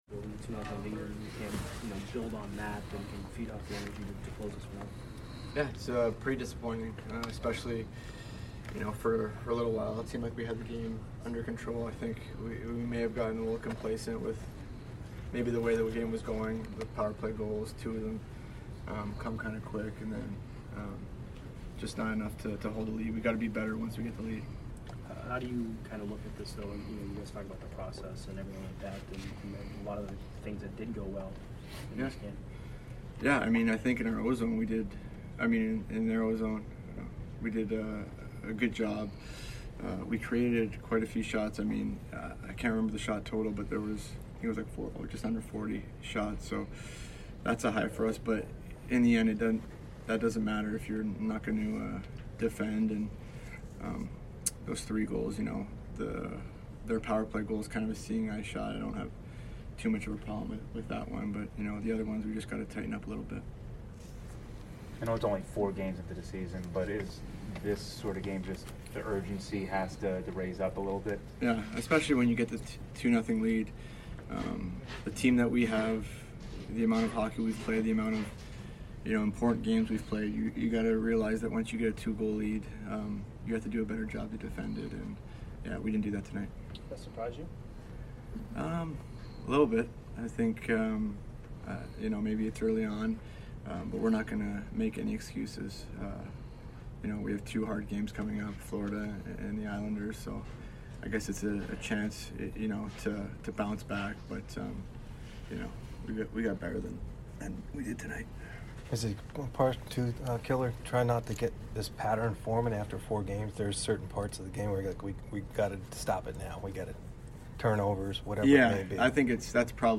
Alex Killorn Post Game 10/18/22 vs. PHI